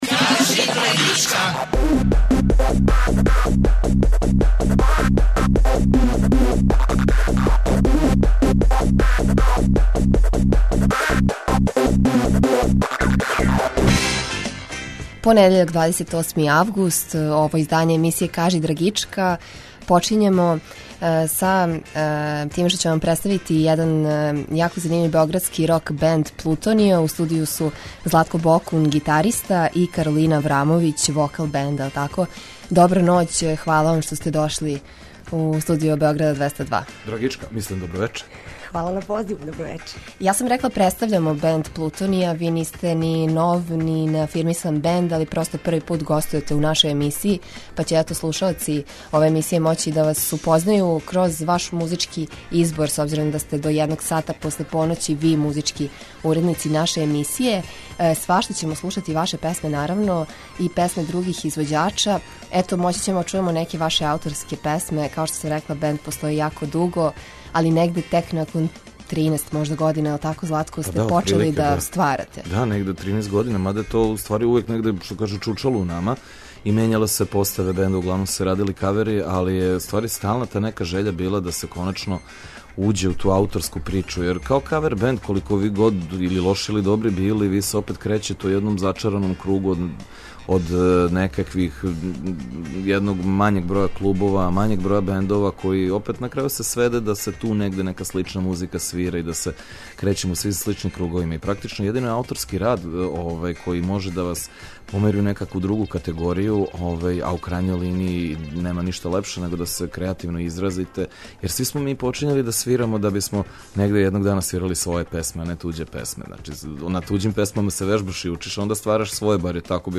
По први пут у нашој емисији гостује београдски рок бенд Плутониа.